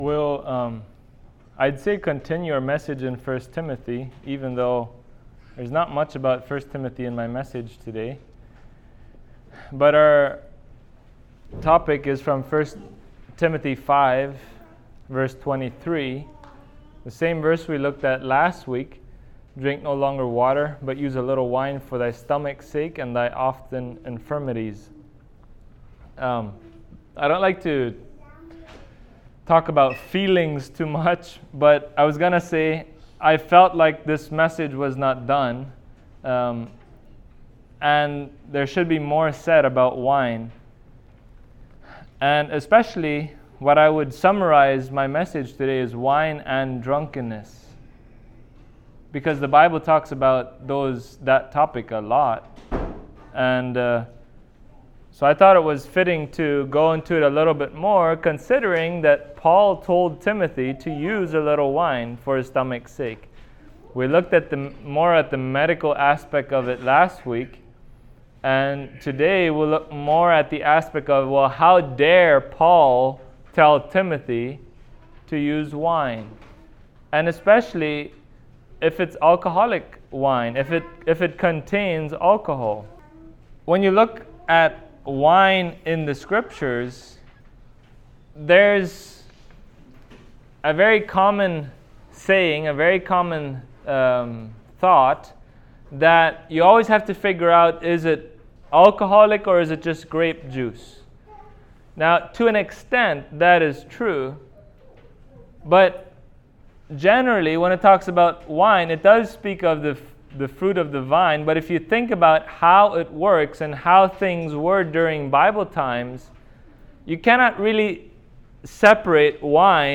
1Tim 5:23 Service Type: Sunday Morning They that tarry long at wine will fall into woe